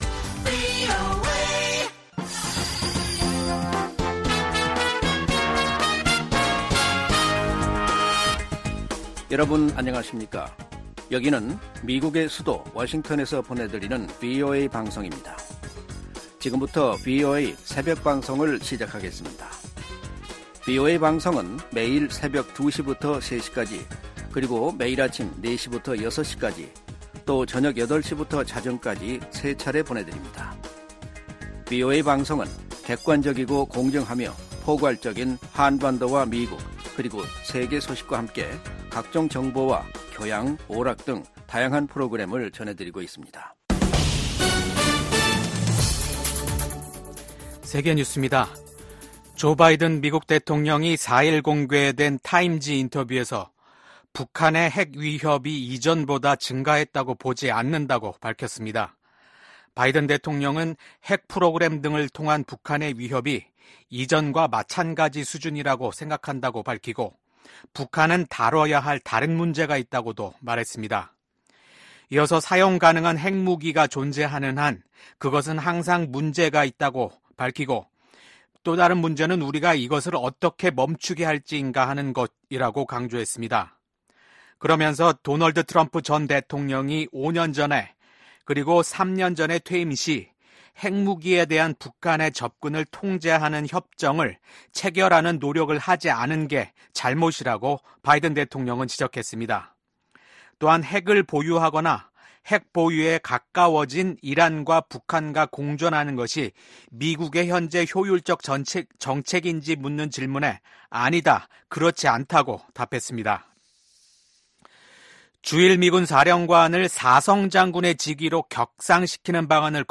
VOA 한국어 '출발 뉴스 쇼', 2024년 6월 5일 방송입니다. 한국 정부는 9.19 군사합의 효력을 전면 정지시킴으로써 대북 확성기 사용과 함께 군사분계선(MDL)일대 군사훈련 재개가 가능하도록 했습니다. 미국의 북한 전문가들은 군사합의 효력 정지로 한국이 대비 태세를 강화할 수 있게 됐으나, 남북 간 군사적 충돌 위험성 또한 높아졌다고 진단했습니다.